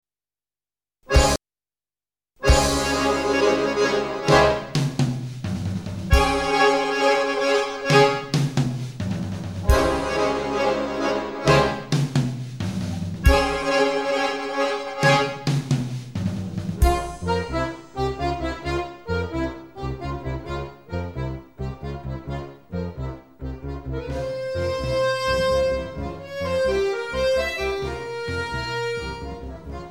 Recorded: Airforce Recording Studios Limited